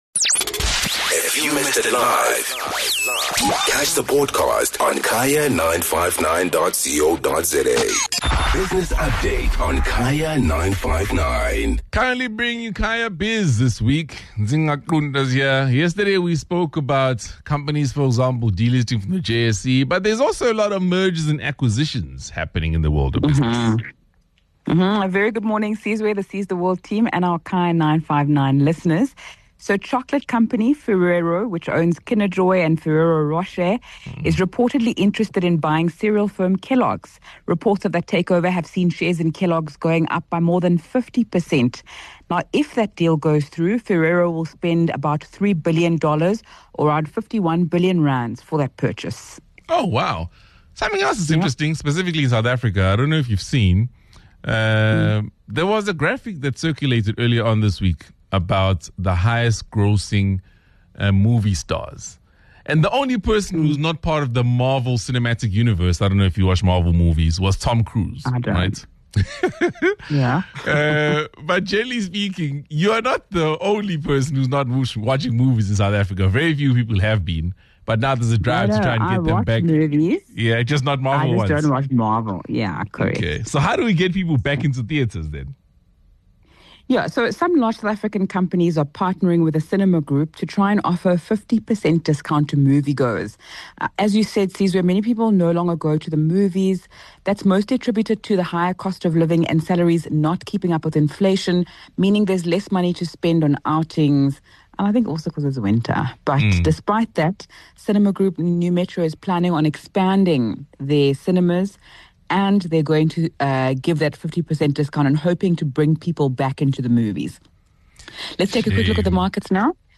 10 Jul Business Update: Ferrero's reported interested in buying cereal firm Kellogg’s.